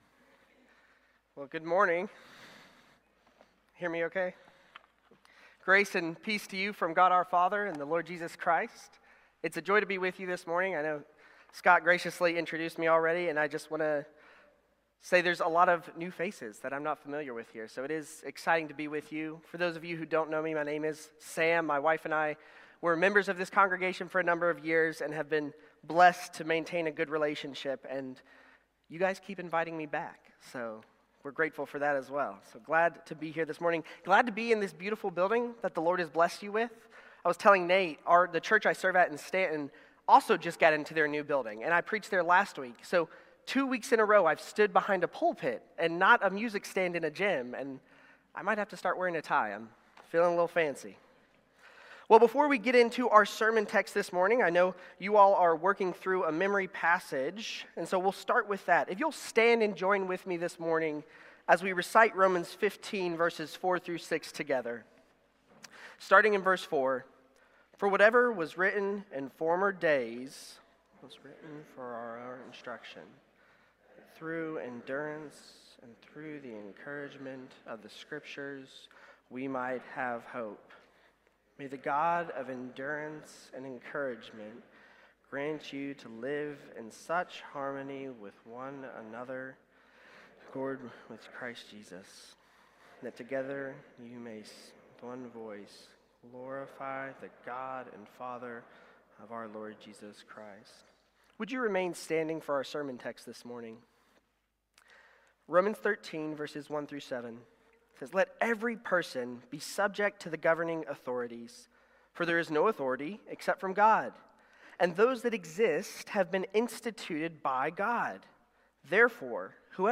March-30-Worship-Service.mp3